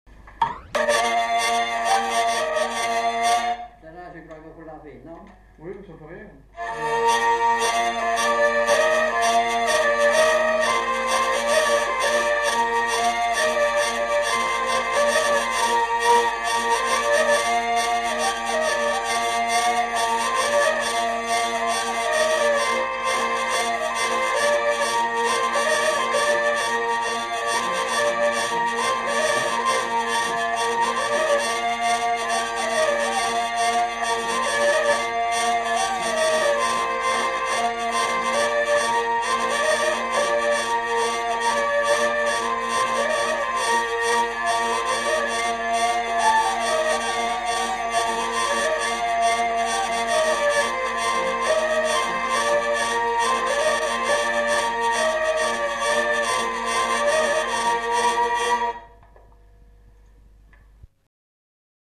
Lieu : Lencouacq
Genre : morceau instrumental
Instrument de musique : vielle à roue
Danse : scottish